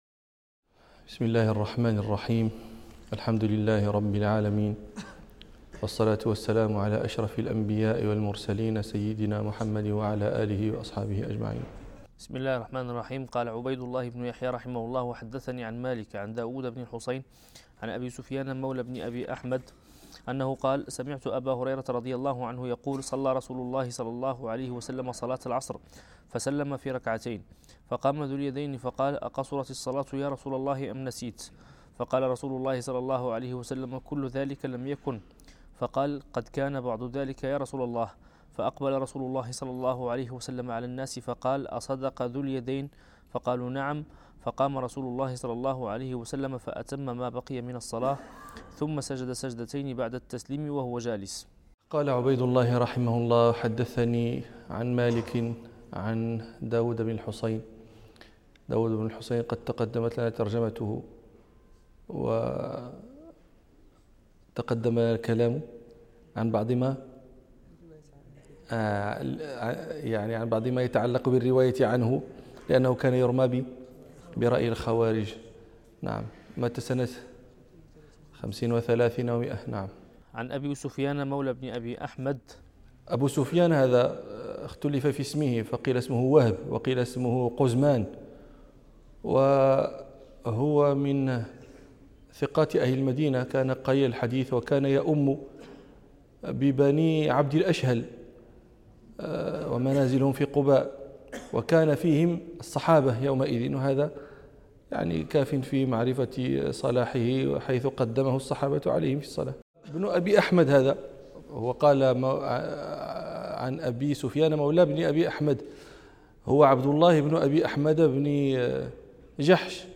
الدرس الثمانون من دروس كرسي الإمام مالك